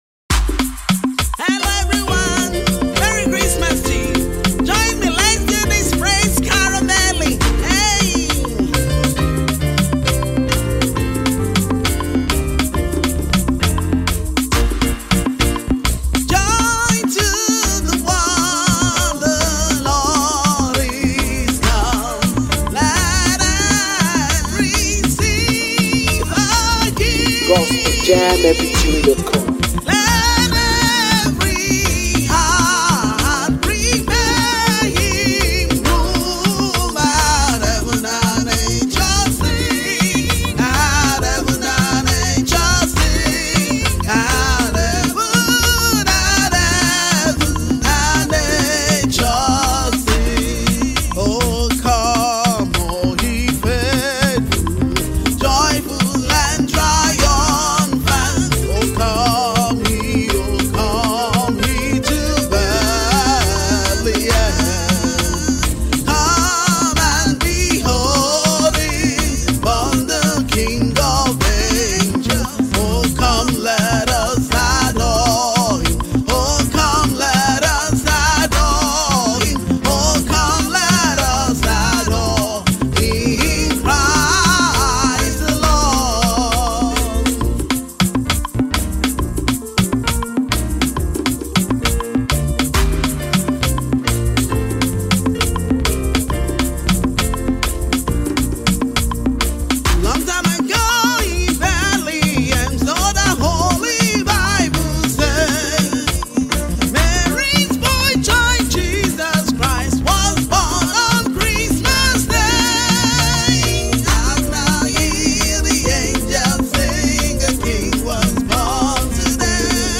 festive rhythms, soulful worship, and energetic praise tunes